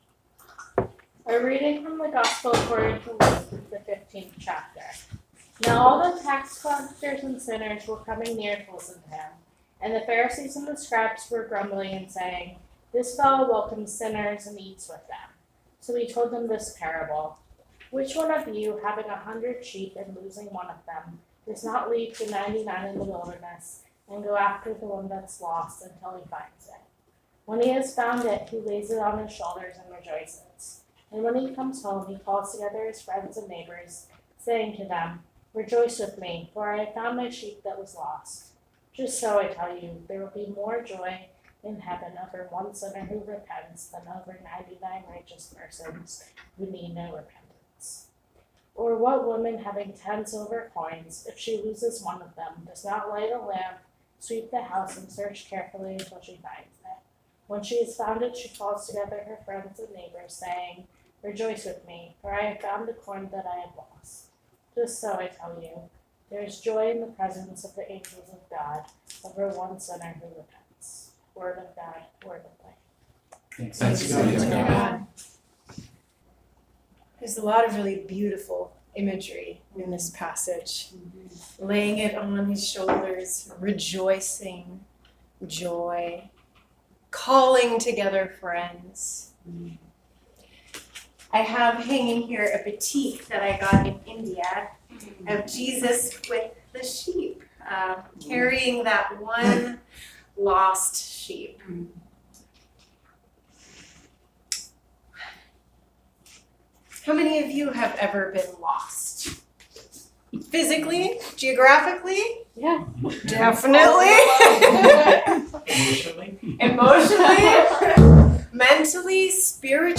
September 16, 2019 Sermon